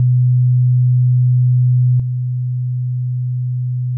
[mp3]       50Hzの音データ/基準音と低減音 [自動車の低音マフラーなど] 80Hzの音データ/基準音と低減音 [いびきや大型犬の鳴き声など] 100Hzの音データ/基準音と低減音 [会話音[男性の声]など]